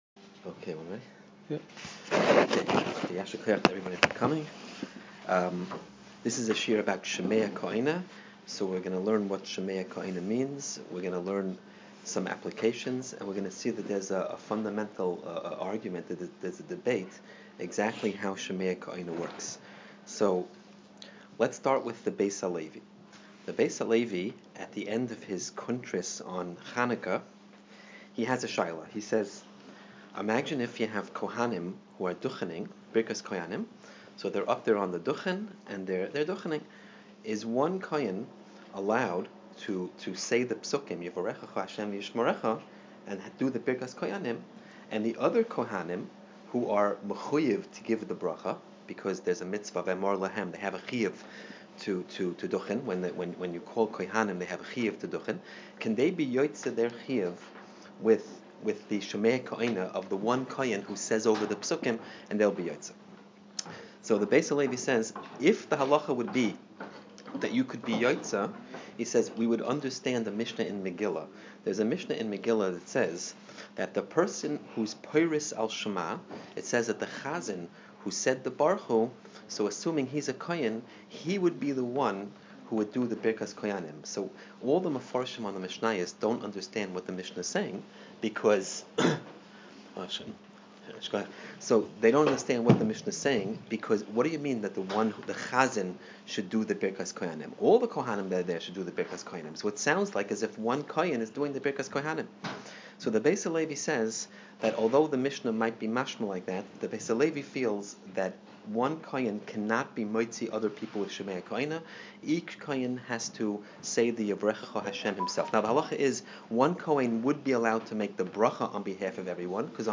Missed the Shiur on Wednesday?